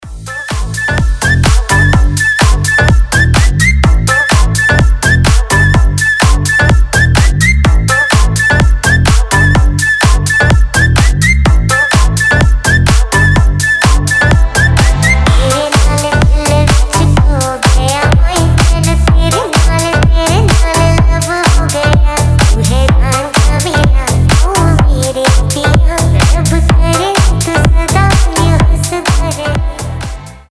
• Качество: 320, Stereo
громкие
веселые
басы
дудка
slap house
Прикольный рингтон с духовым исполнением